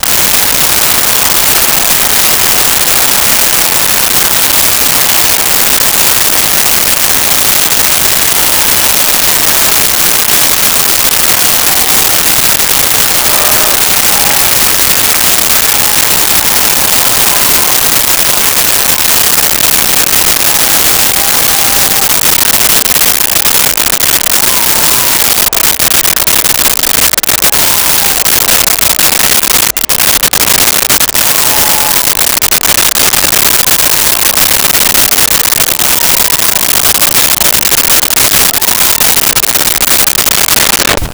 Crowd Applause And Walla
Crowd Applause And Walla.wav